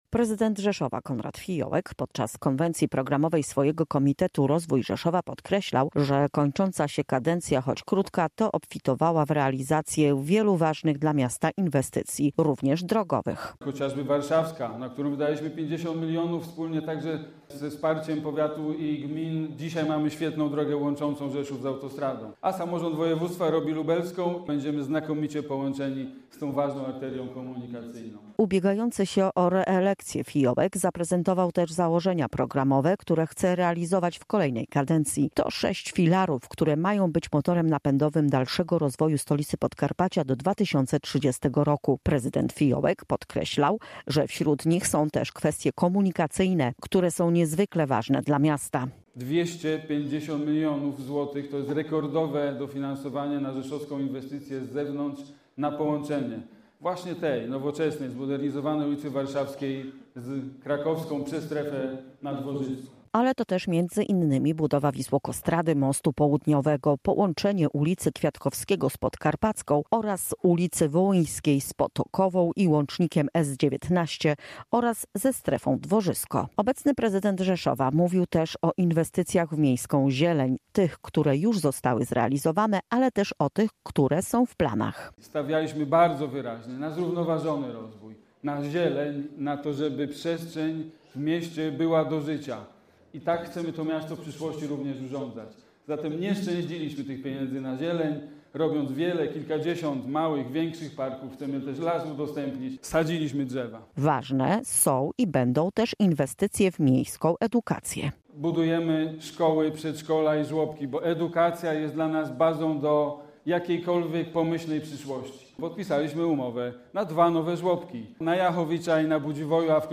Konwencja Rozwoju Rzeszowa. Założenia programowe
Relacja